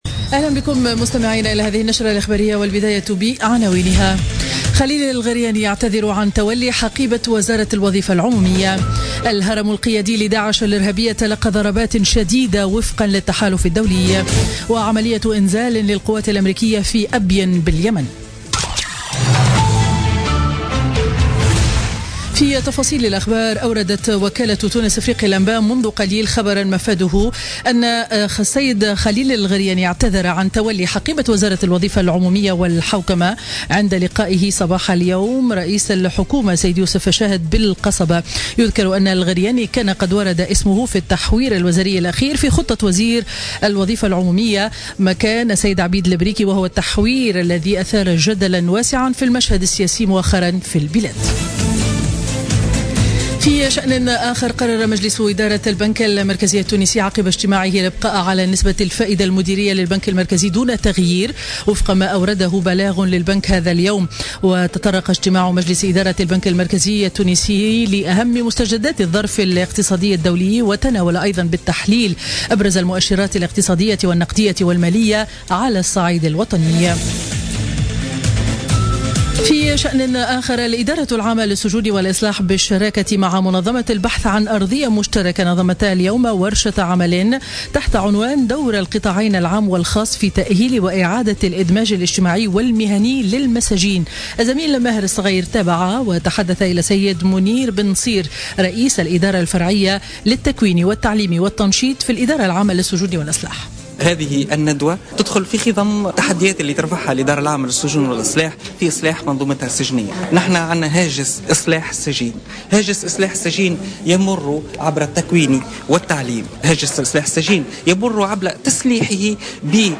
نشرة أخبار منتصف النهار ليوم الخميس 2 مارس 2017